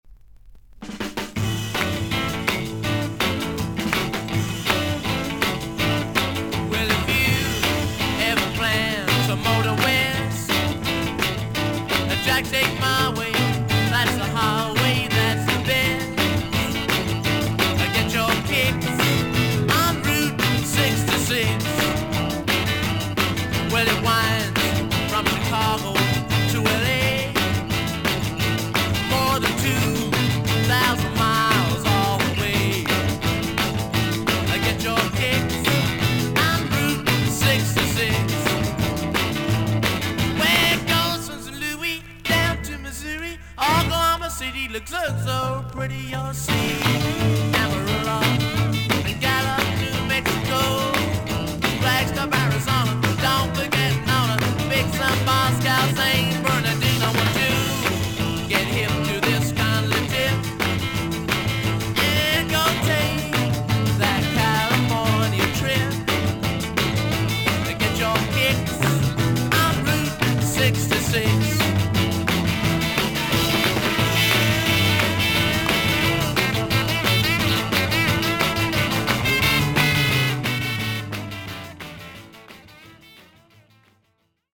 ほんの少々サーフィス・ノイズの箇所あり。両面2〜3曲目に1つ浅いプレスミスあり。音に影響はありません。